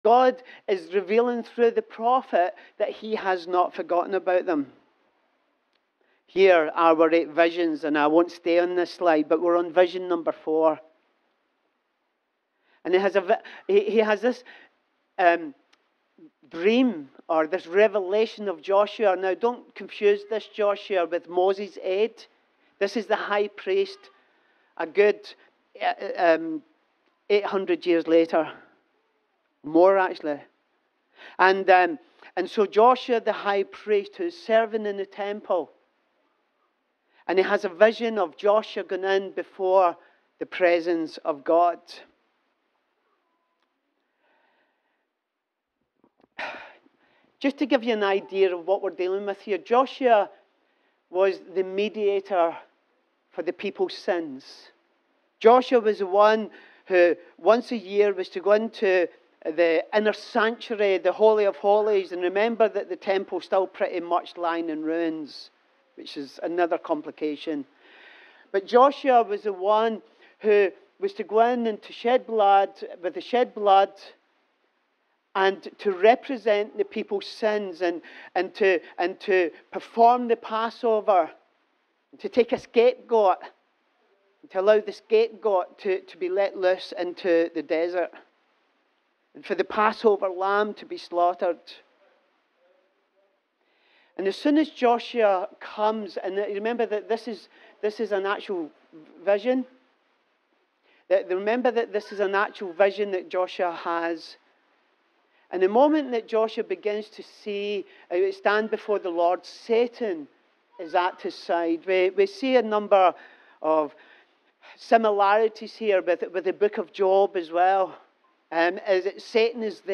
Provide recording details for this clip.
Sunday-Service-_-Zechariah-Part-5_-Laundry-Day-_-13th-February.mp3